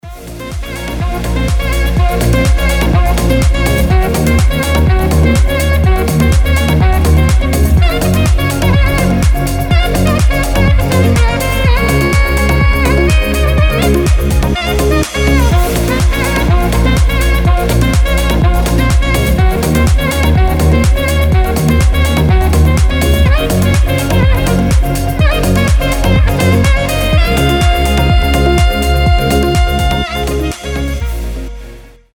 Эмоциональная игра на саксофоне